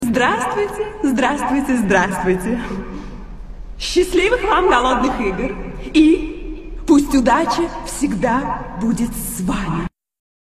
schastlivykh vam golodnykh igr Meme Sound Effect
Category: Movie Soundboard